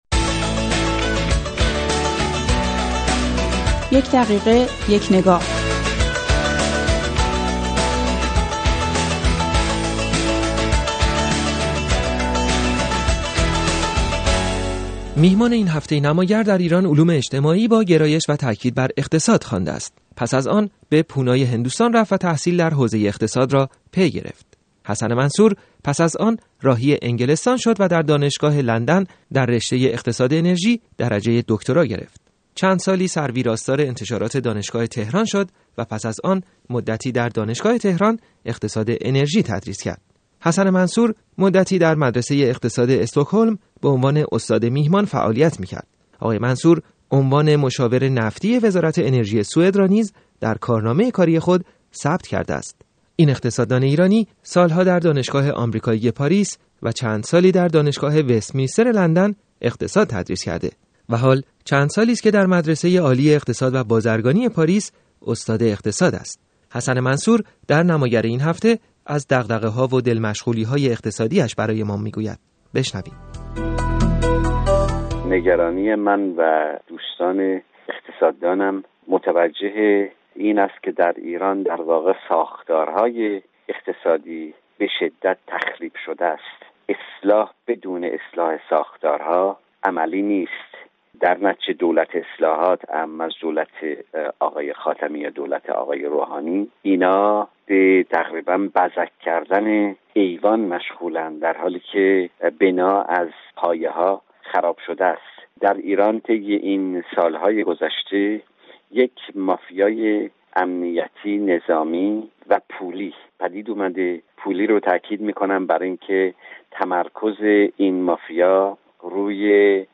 گفتار اقتصادی